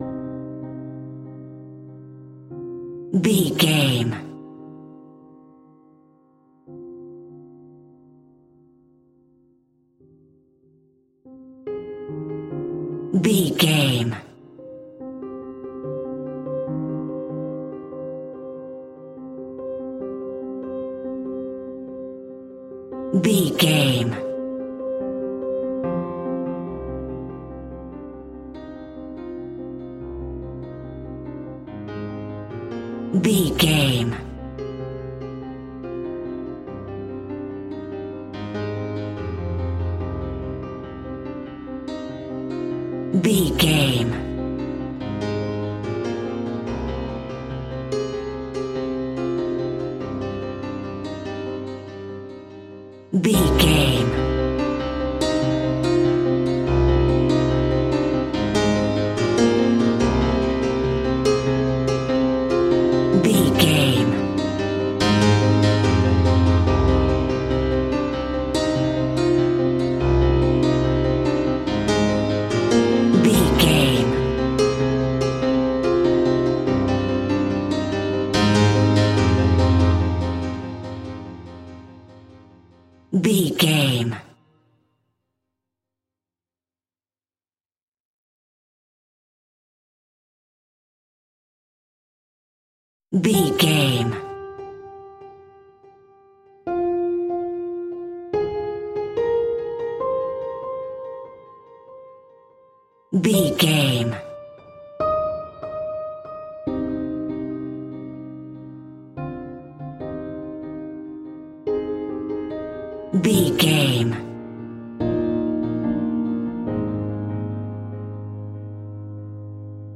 Ionian/Major
E♭
dramatic
strings
percussion
synthesiser
brass
violin
cello
double bass